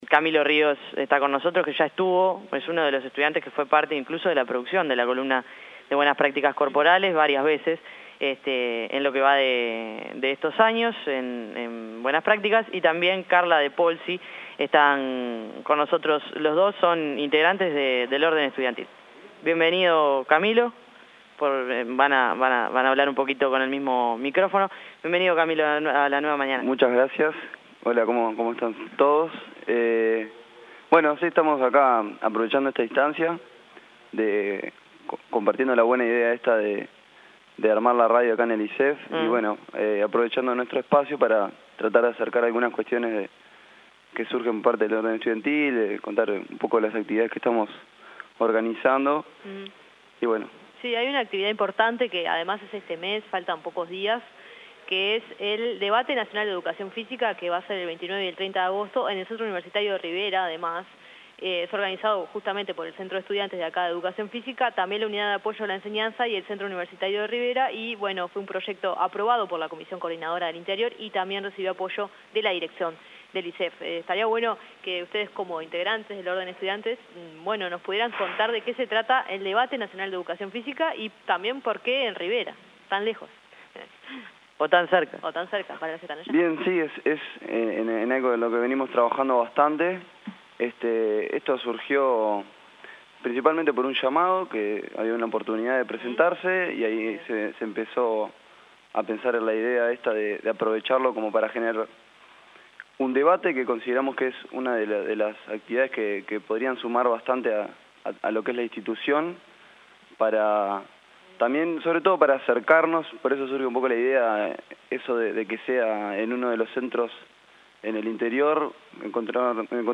Audio: Entrevista a integrantes del orden de estudiantes de ISEF
En la transmisión especial que La Nueva Mañana realizó este lunes desde el Instituto Superior de Educación Física, hablamos con integrantes del Centro de Estudiantes de Educación Física.